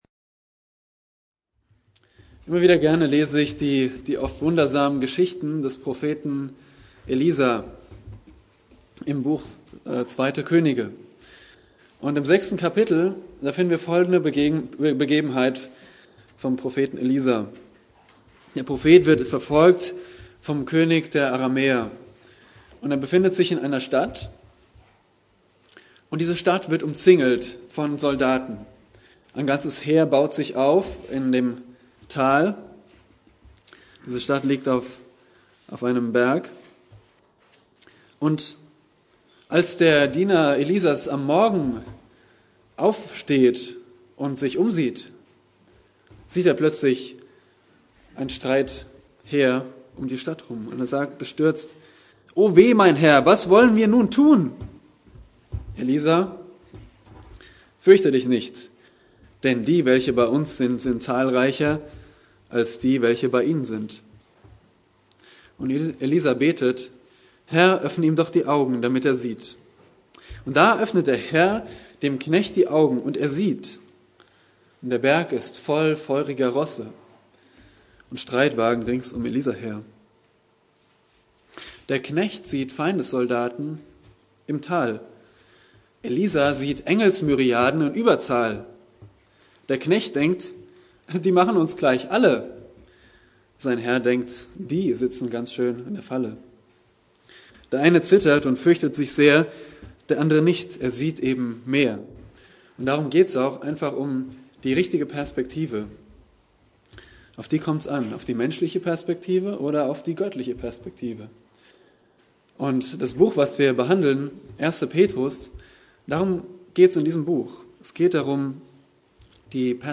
Eine predigt aus der serie "Der erste Petrusbrief."